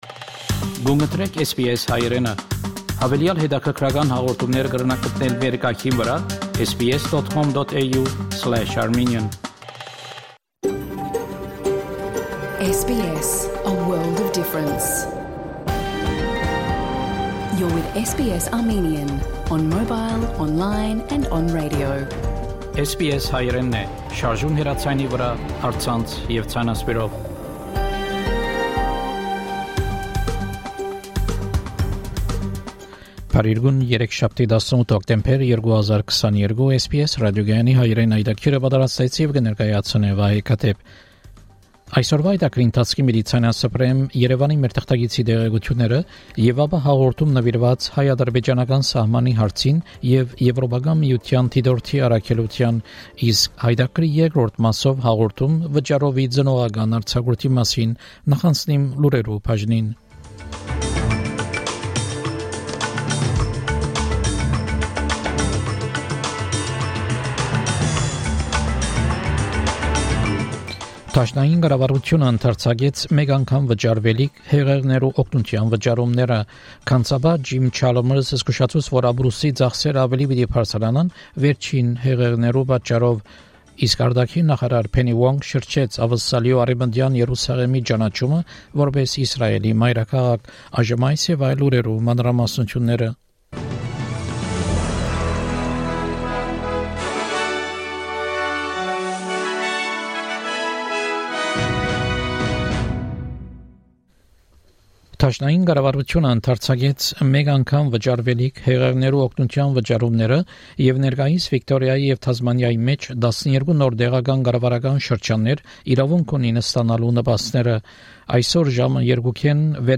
SBS Armenian news bulletin from 18 October 2022 program